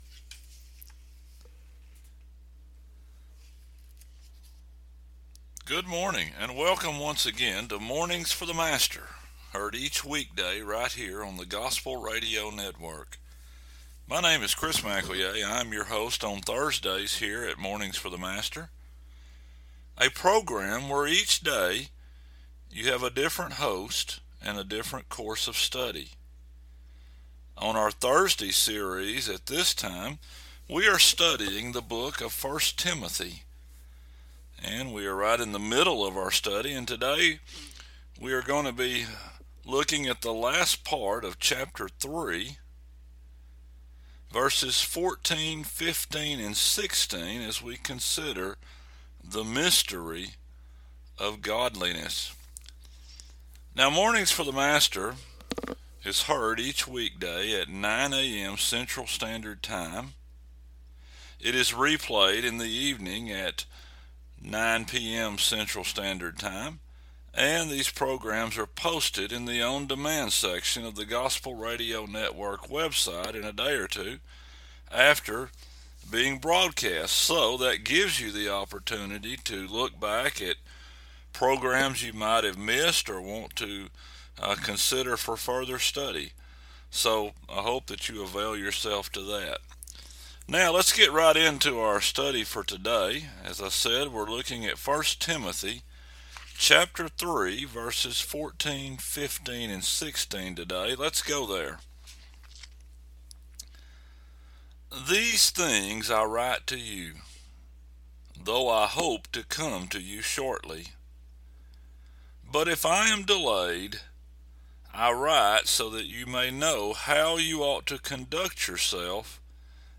Program Info: Live program from the TGRN studio in Mount Vernon, TXClick here for current program schedule.